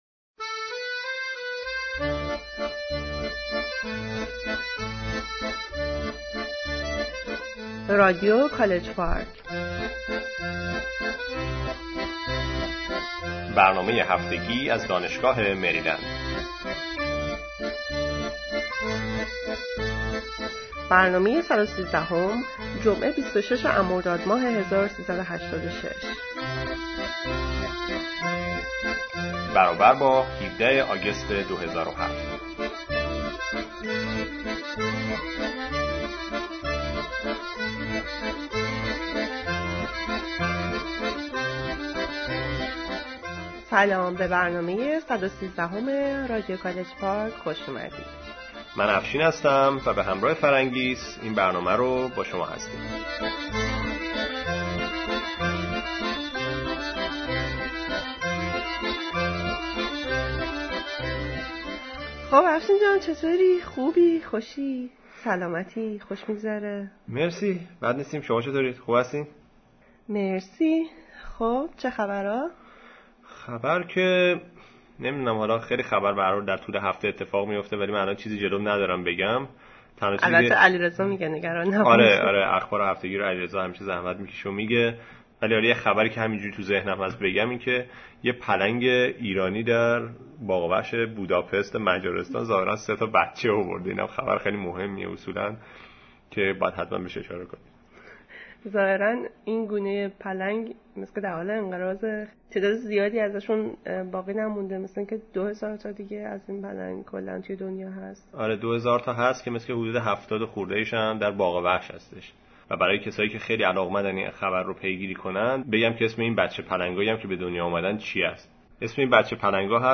Weekly News
An interview with Parviz Shahriari, Mathematician